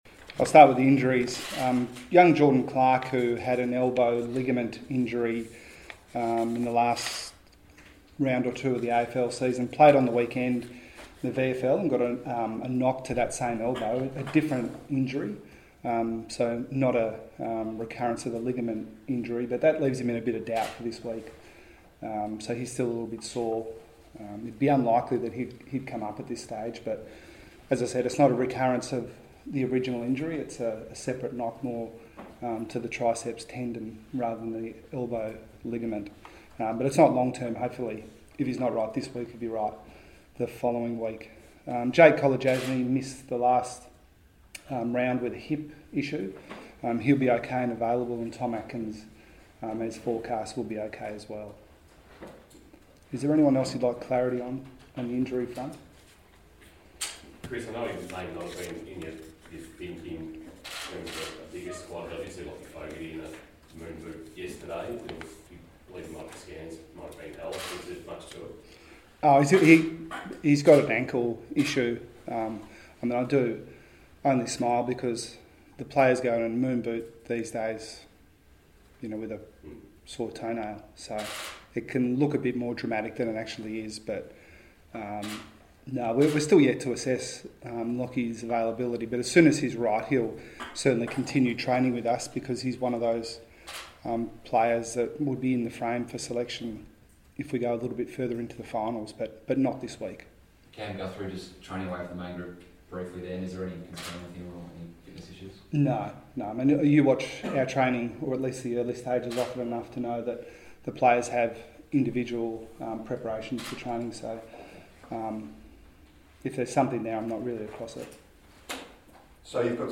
Geelong coach Chris Scott faces the media ahead of Friday night's qualifying final clash with Collingwood.